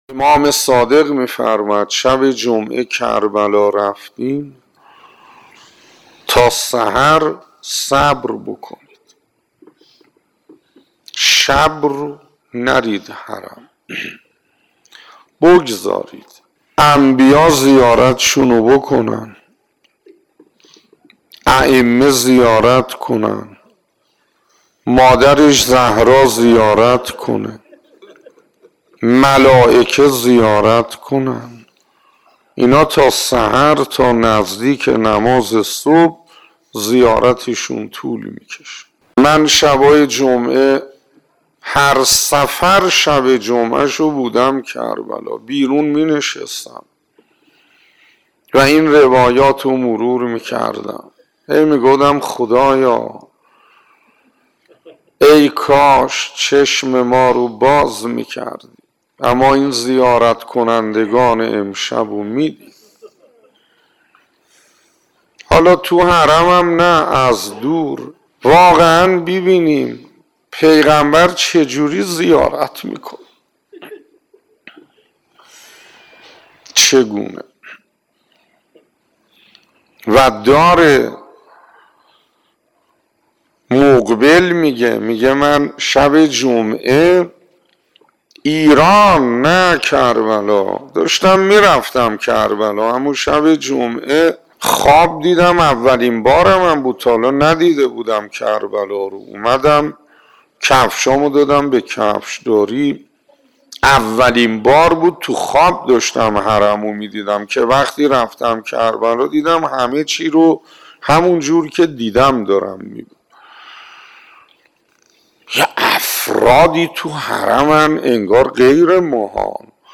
بخشی ازسخنرانی حجت‌الاسلام والمسلمین حسین انصاریان، پیرامون روایت امام صادق (ع) درباره شب زنده‌داری شب جمعه در حرم امام حسین (ع) را بشنوید.